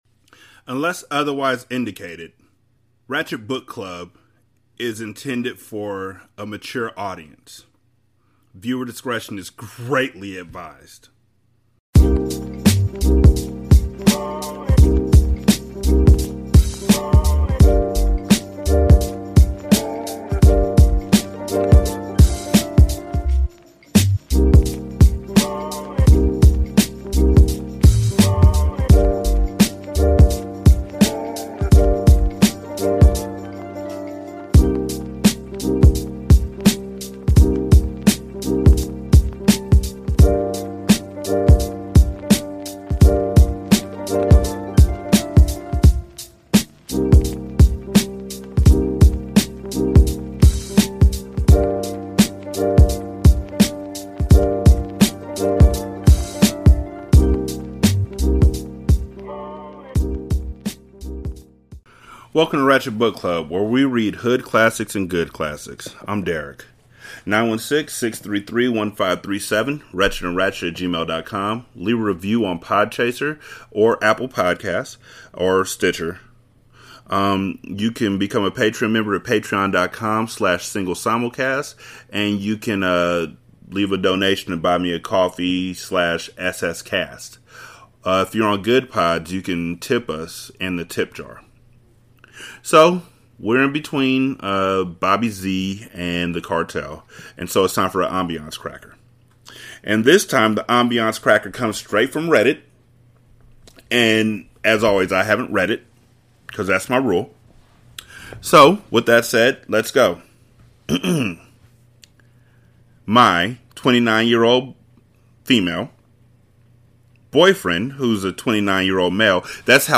Welcome to Ratchet Book Club, where we read Good Classics and Hood Classics alike. In this Ambiance Cracker, I read a Reddit post about a boyfriend who gets his eggs with a side of fists at the Waffle House.